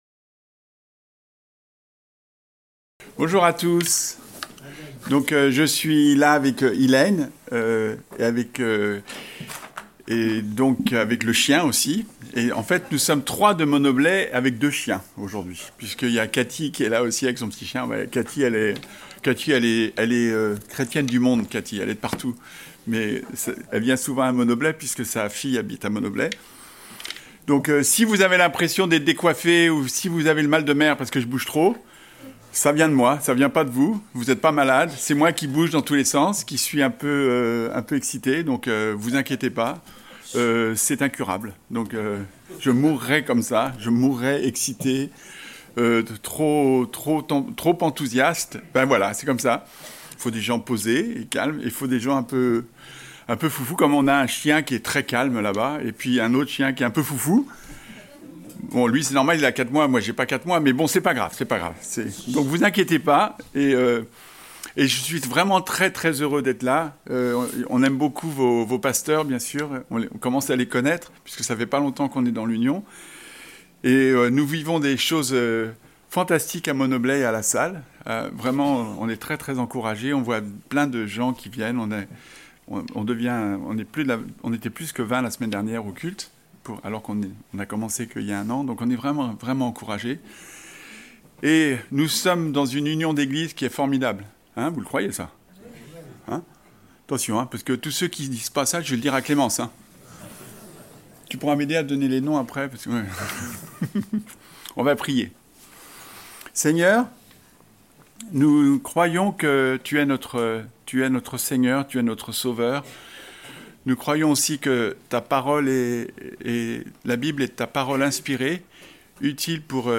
Prédication du 22 février 2026.